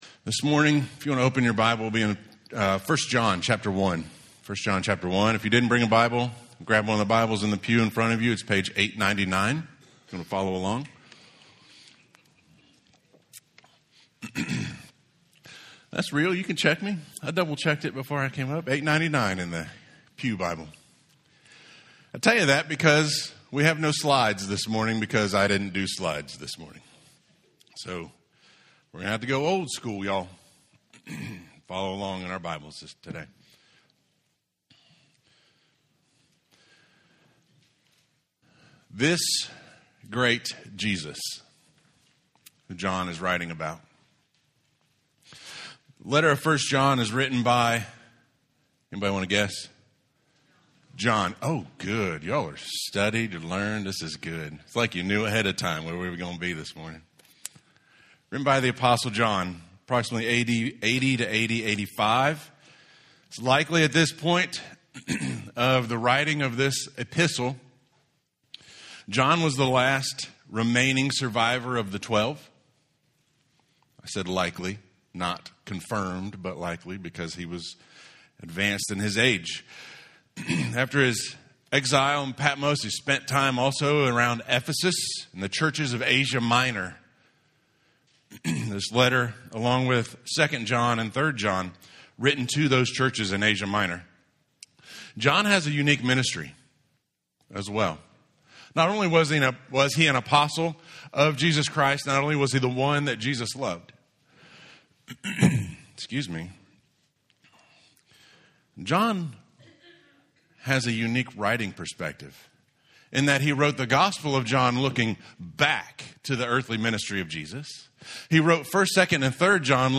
1John 1:1-4 Audio Sermon